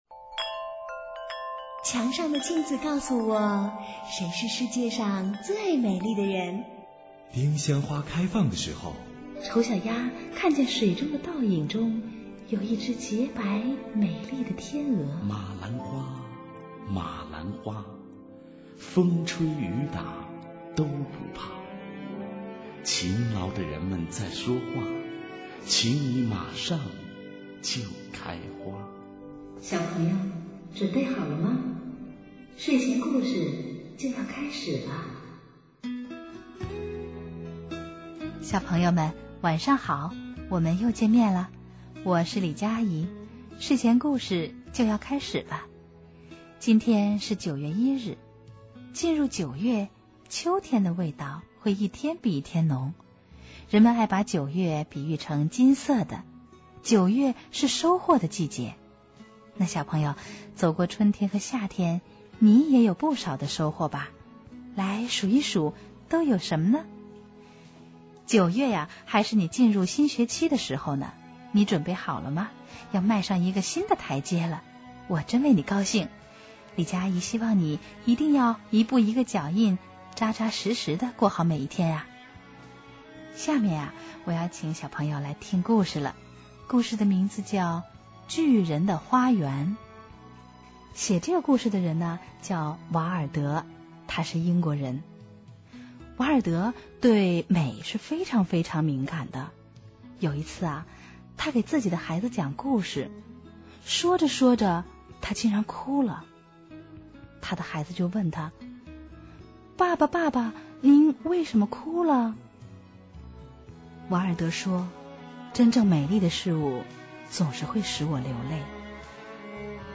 睡前故事